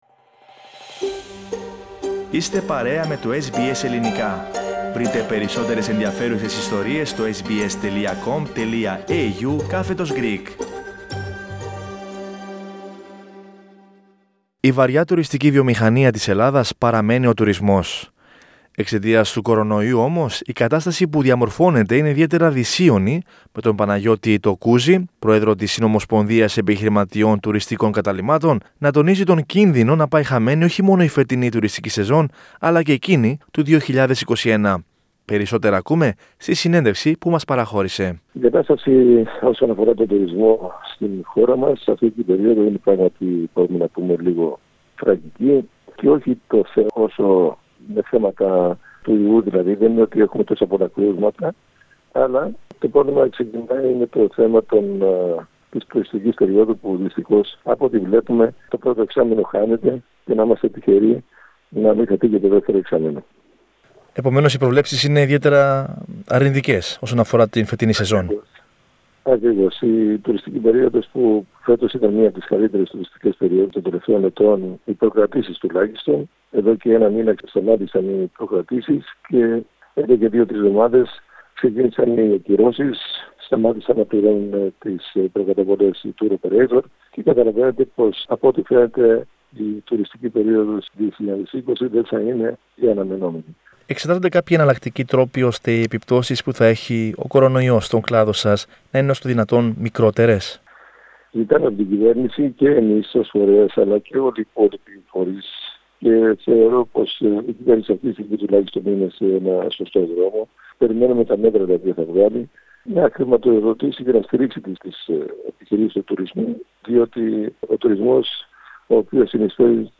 Σε συνέντευξη του στο Ελληνικό Πρόγραμμα της Δημόσιας Ραδιοφωνίας SBS